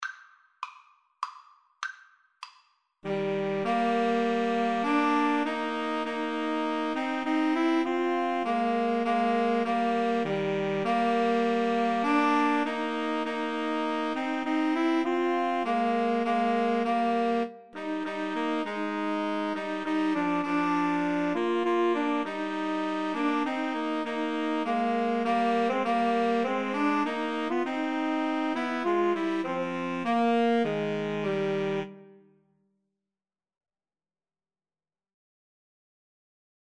Alto SaxophoneTenor SaxophoneBaritone Saxophone
3/4 (View more 3/4 Music)
Eb major (Sounding Pitch) (View more Eb major Music for Woodwind Trio )
Woodwind Trio  (View more Easy Woodwind Trio Music)
Traditional (View more Traditional Woodwind Trio Music)